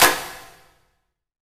HEX CLAPS.wav